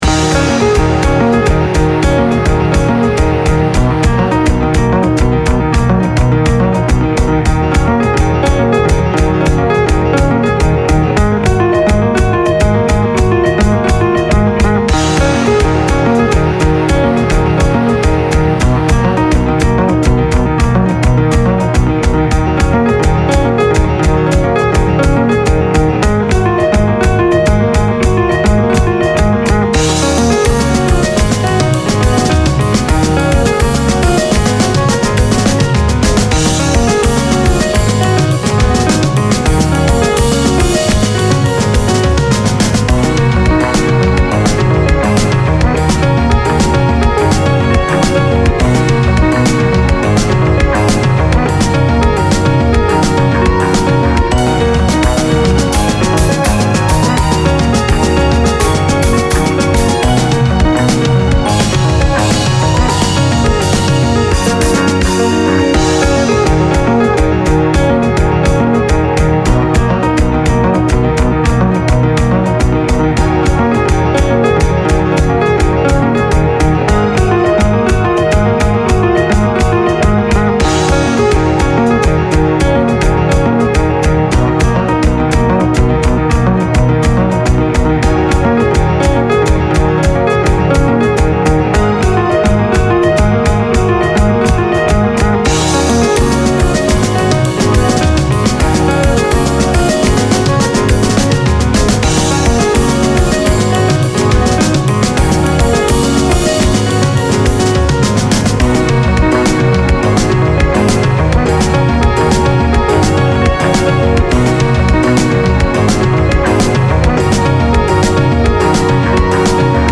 Piano, Bass, Orchestration
Guitar
Drums